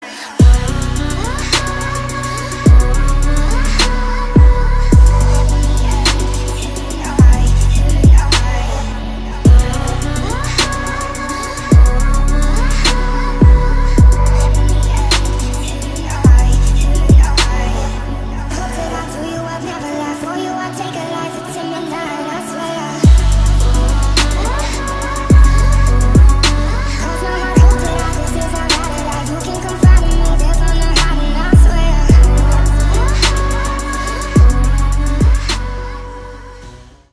• Качество: 320, Stereo
громкие
мощные
deep house
атмосферные
женский голос
Electronic
Атмосферный клубнячок